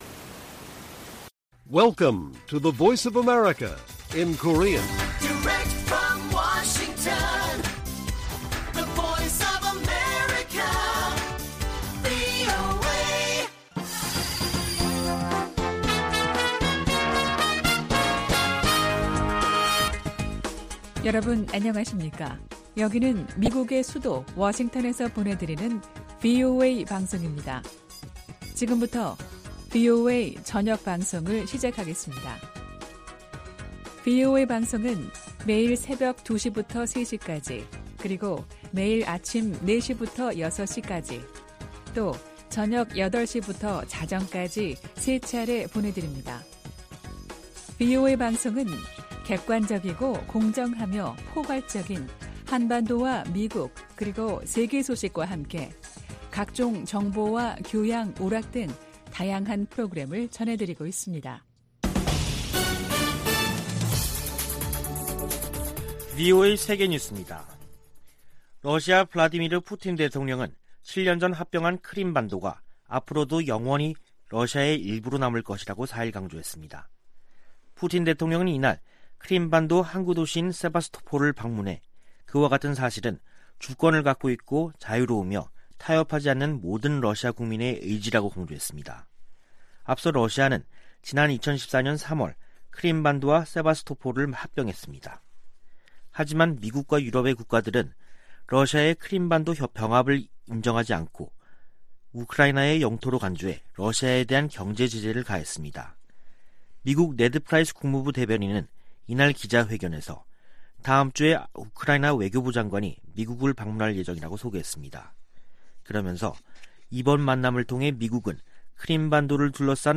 VOA 한국어 간판 뉴스 프로그램 '뉴스 투데이', 2021년 11월 5일 1부 방송입니다. 북한의 불법 무기 프로그램 개발을 막기 위해 전 세계가 유엔 안보리 대북제재를 이행할 것을 미 국무부가 촉구했습니다. 또한 국무부는 북한의 사이버 활동이 전 세계에 위협인 만큼 국제사회 협력이 필수적이라고 밝혔습니다. 뉴질랜드가 안보리 대북제재 위반 활동 감시를 위해 일본 해상에 항공기를 배치합니다.